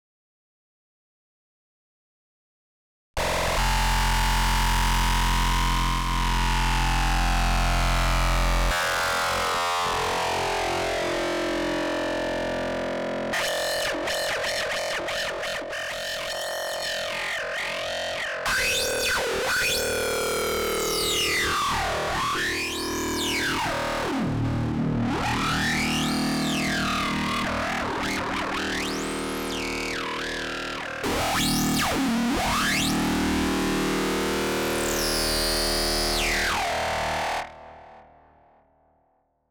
Distord that B1tch Lead - Audionerdz Academy